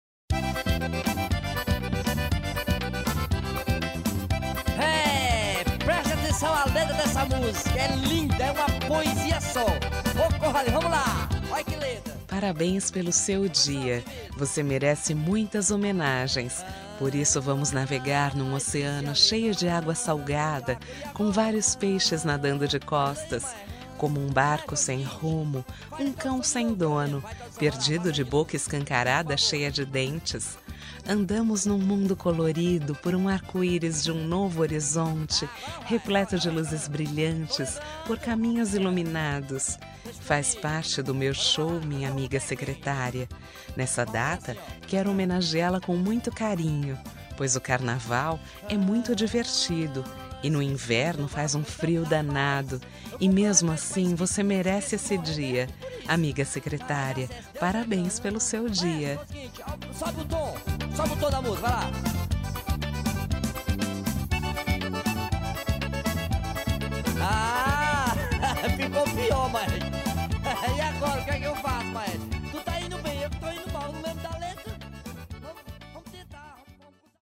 Aniversário de Humor – Voz Feminina – Cód: 200115